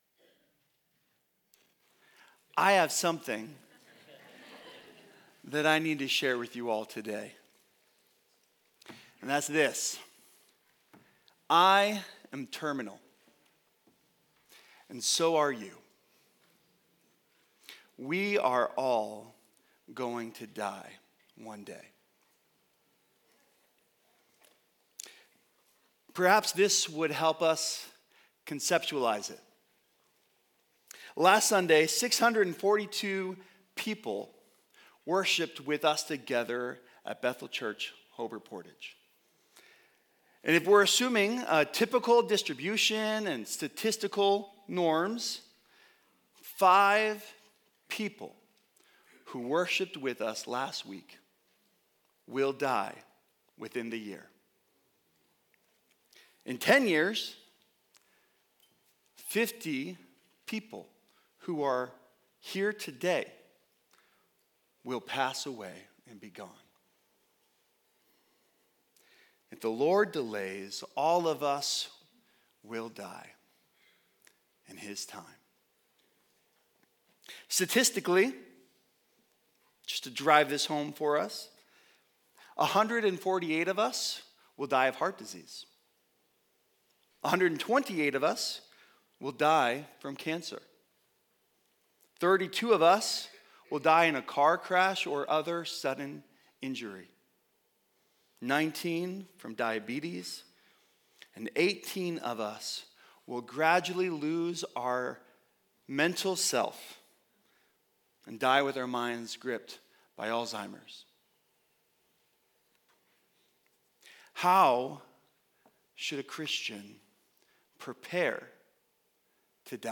| Image of God - HP Campus Sermons